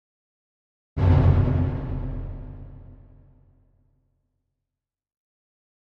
Drum Deep Symphonic - Orchestra Drum Single Hit - Deep And Reverberant